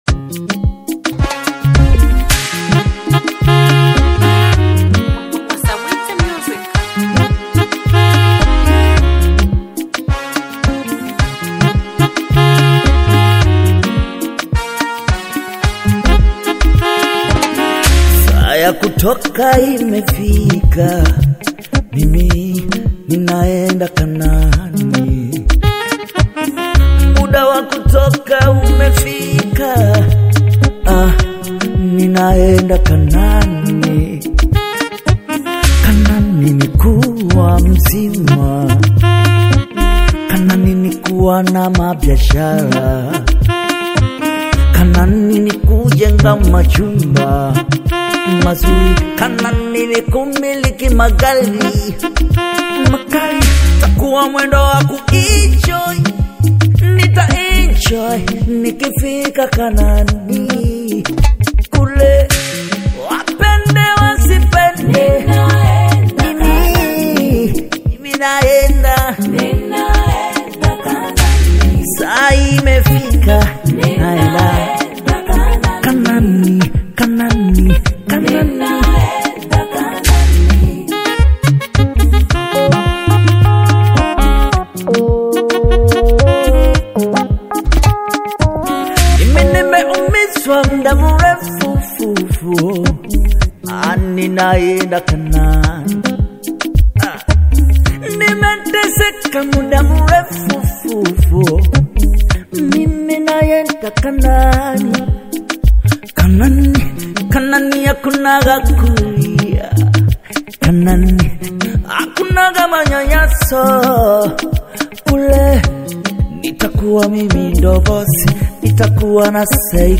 a high-energy and spiritually charging single